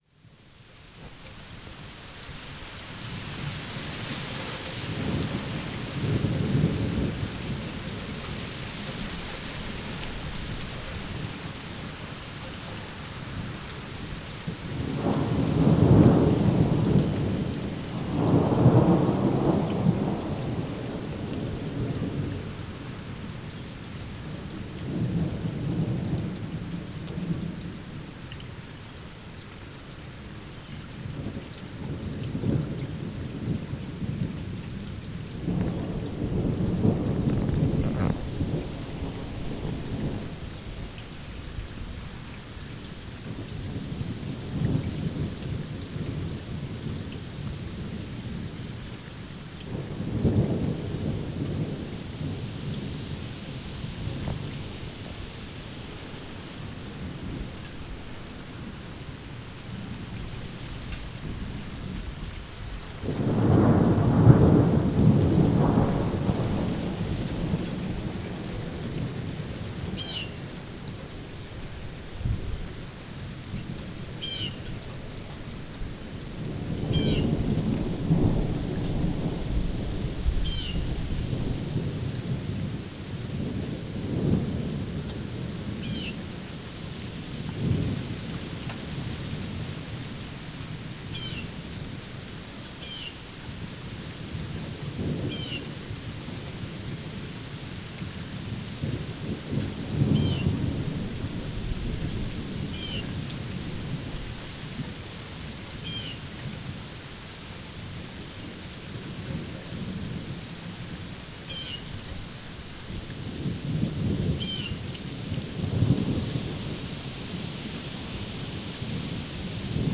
ambiant